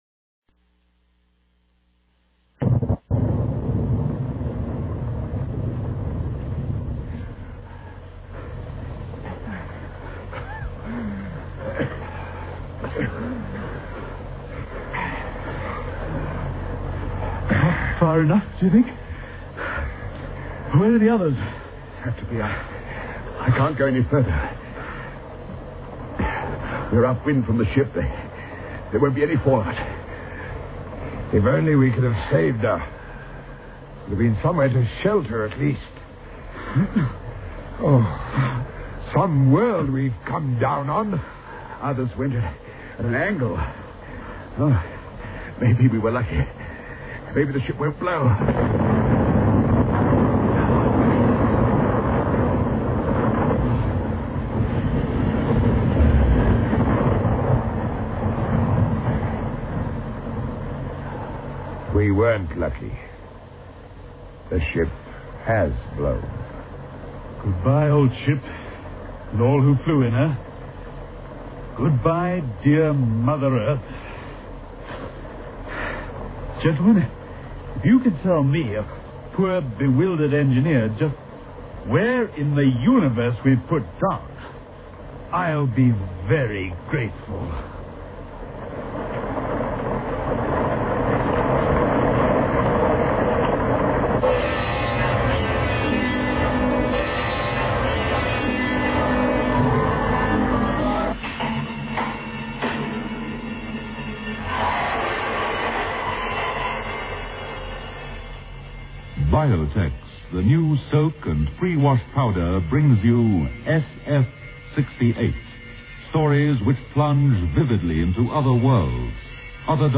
SF68 - SABC Radio Broadcast 1968
(Radio Adaptation)